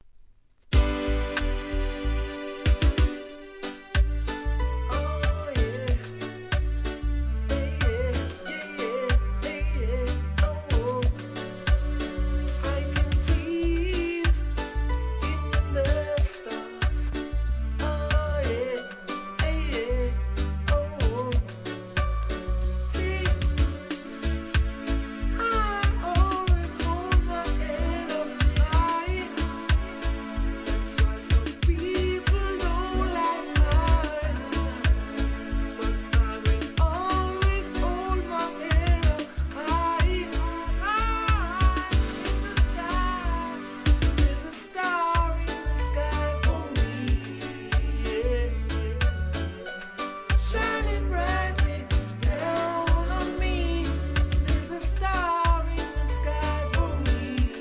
初期UKコンシャスREGGAE